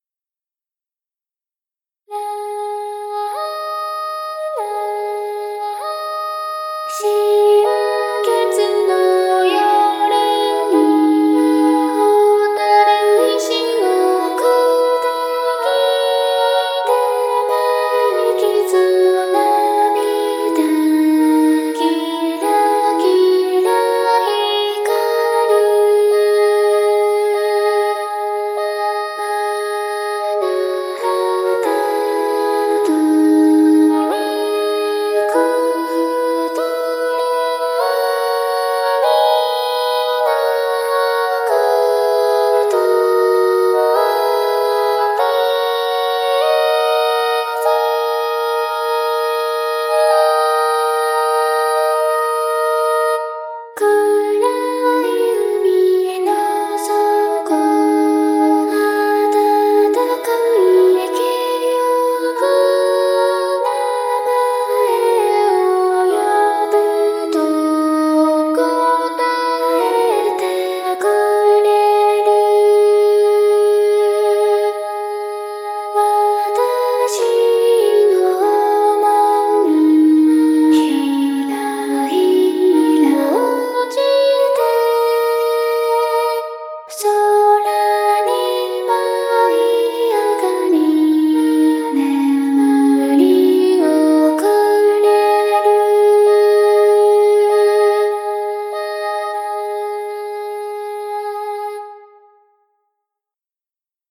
・１曲目を無料のボカロみたいなやつ（UTAU + 闇音レンリ1.5）だけでやりました
・主旋律に２つの副旋律を加えて多声音楽のようにしました（主に３声で一部４声です）。
・曲の途中に西洋の宗教音楽的な部分を入れました。